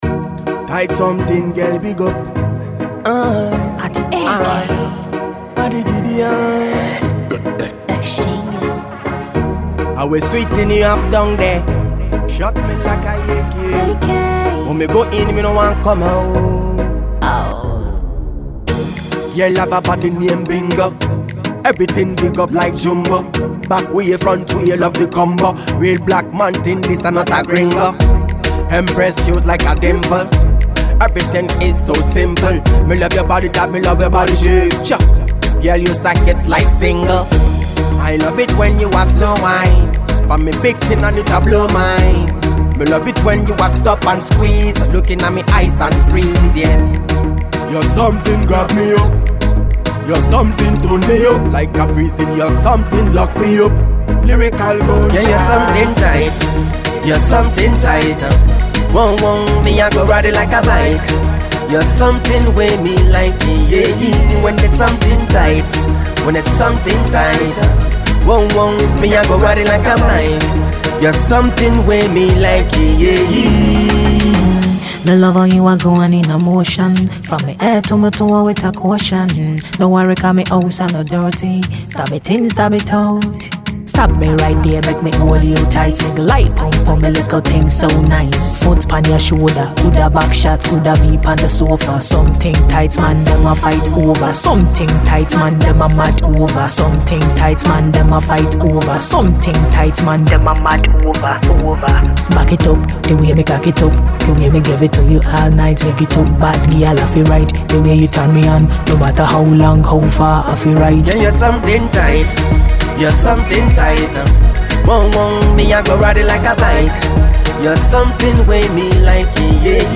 Reggae Dancehall
Club Banger